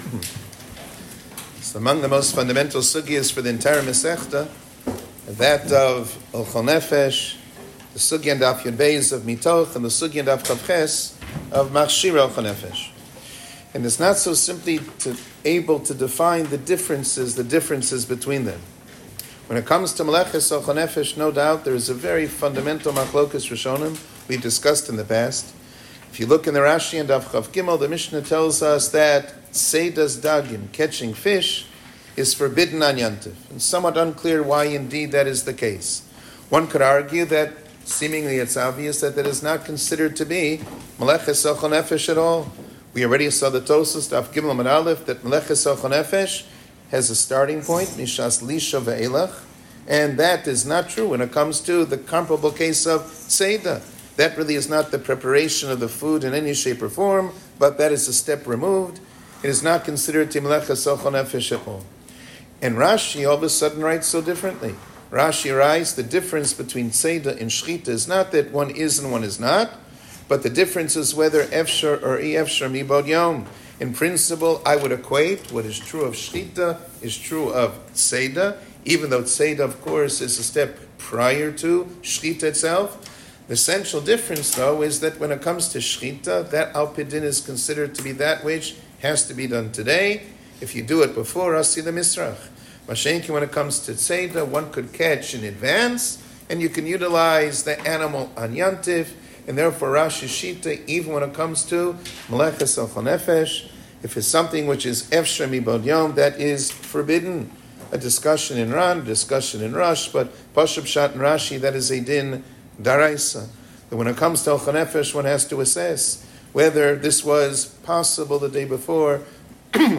שיעור כללי - מלאכת אוכל נפש ביום טוב